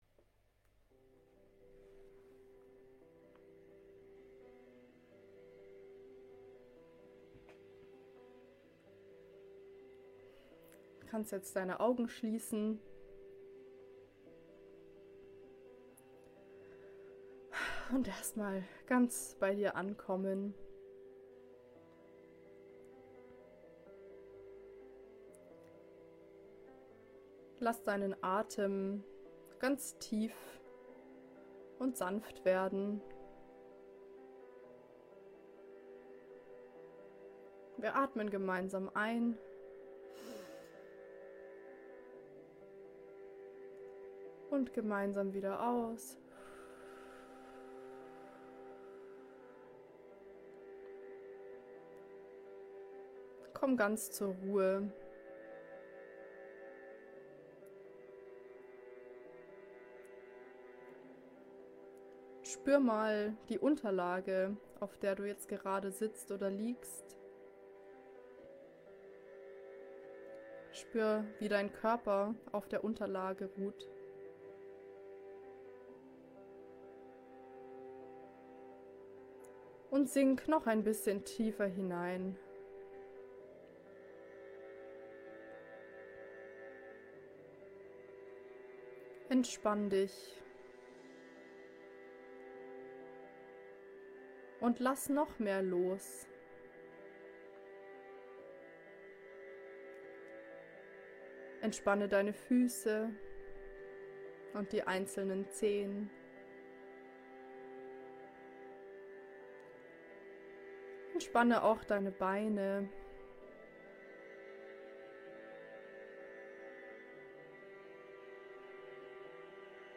Traumberuf Meditation (MP3-Audio)
Licht an Akademie - Traumjob Meditation.mp3